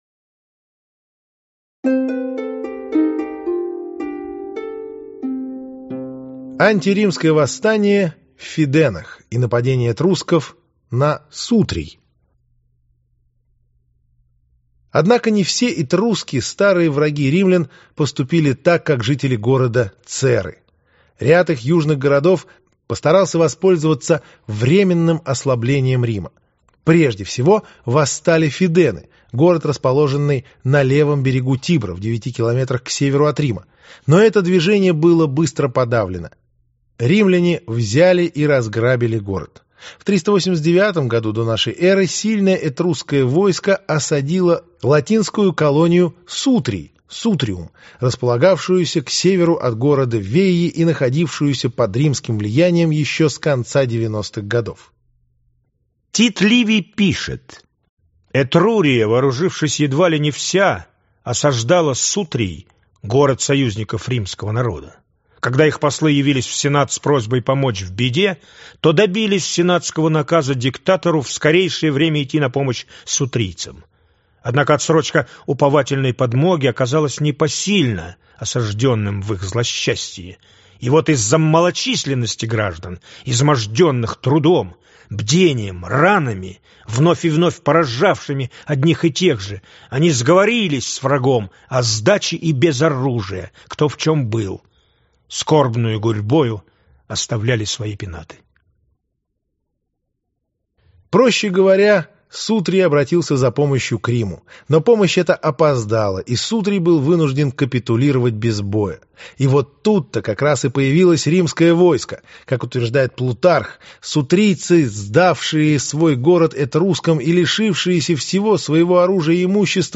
Аудиокнига Этруски: тайные страницы европейской истории | Библиотека аудиокниг